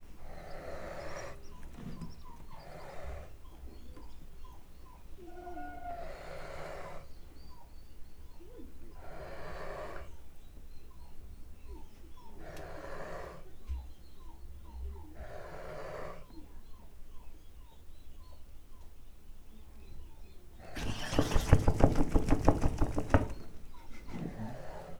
csibeitfujva_esszarnyatcsattogtatvavedi00.25.wav